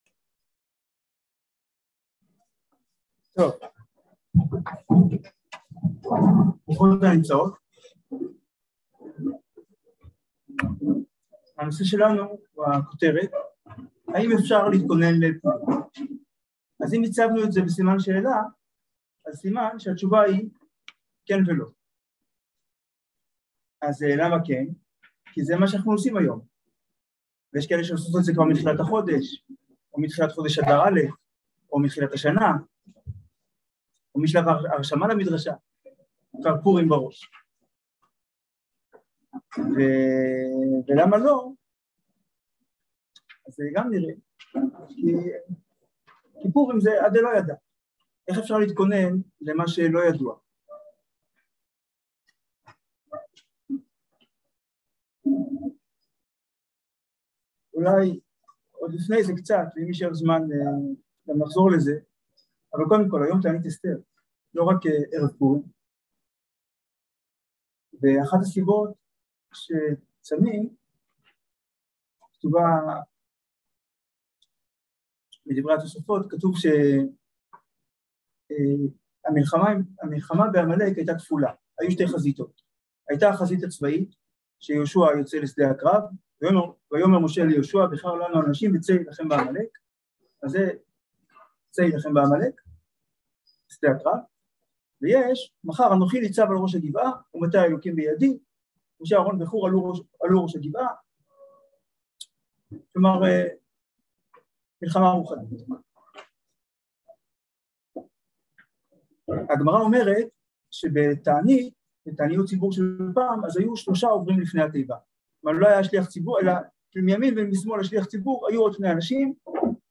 האם אפשר להתכונן לפורים? | יום עיון תשפ"ב | מדרשת בינת